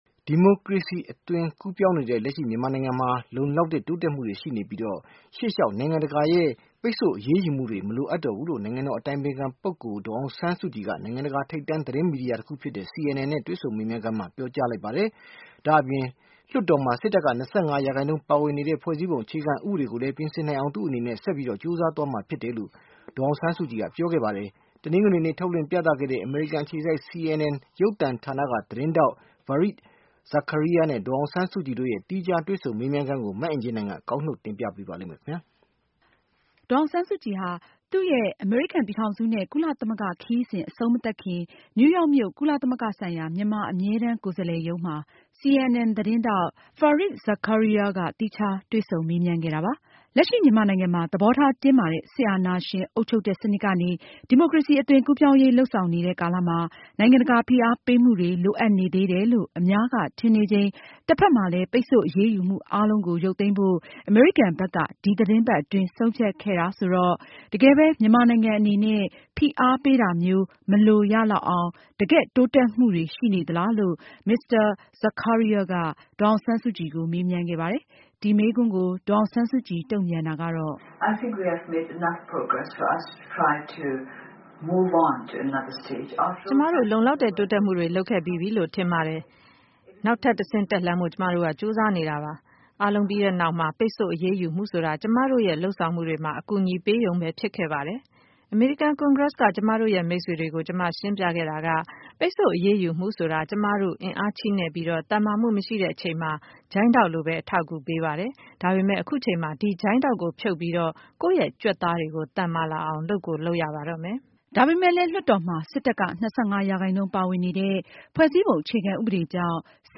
ဒေါ်စုနဲ့ CNN ရုပ်သံဌာန တွေ့ဆုံ မေးမြန်းခန်း